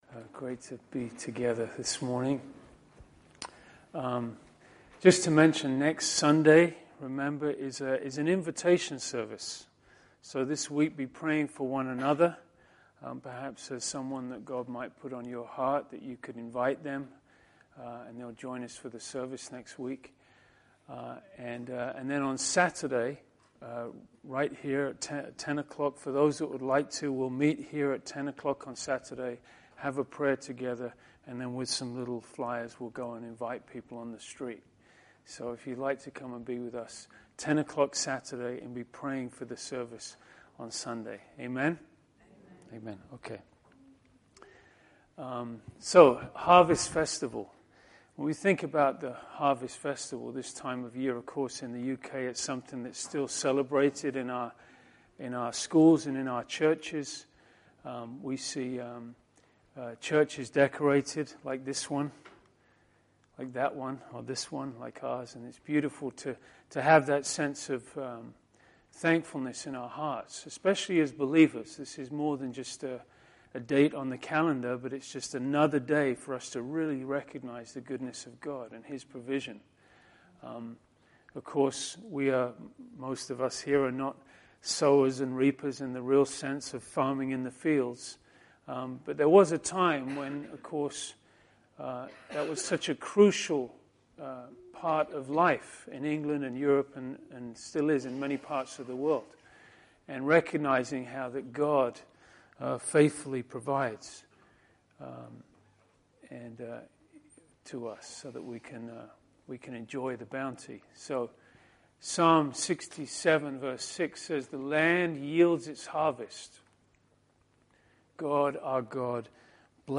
For this Harvest Festival Service we explore The Jewish Harvest Festival or "The Feast of Tabernacles" (Sukkpot). It was at this Feast that Jesus gave his famous invitation to all those who thirst.